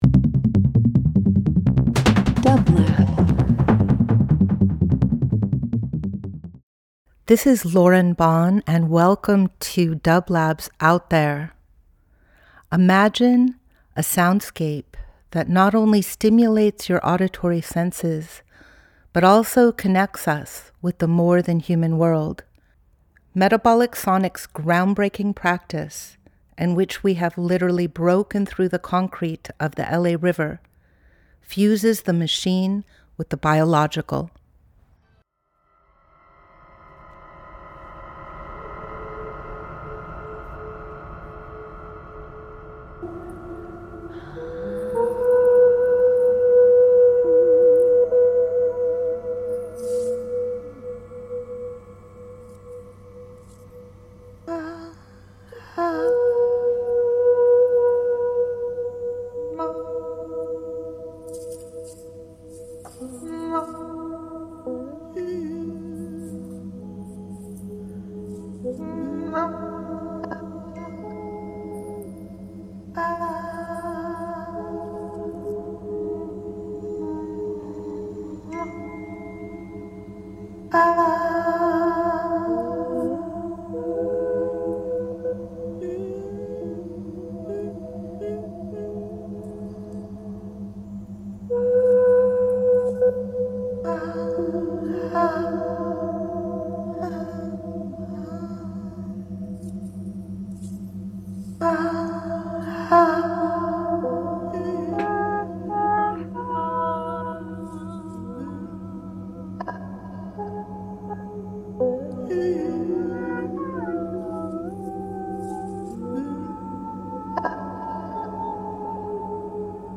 Each week we present field recordings that will transport you through the power of sound.
Metabolic Sonics Metabolic Studio Out There ~ a field recording program: Singing into the Silo 04.16.26 Ambient Voyage with dublab into new worlds.
This is a mix of archival recordings – the Interdependence Choir singing into the silo from many different locations across the Los Angeles area and across the country connecting in the echo of the silo and the massive Aeolian Harp strung along its sides.